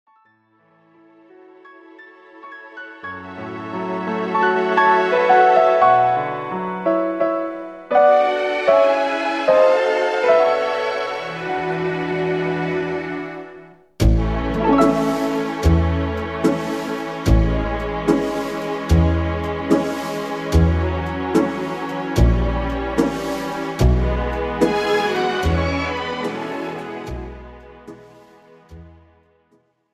This is an instrumental backing track cover.
• Key – A
• Without Backing Vocals
• No Fade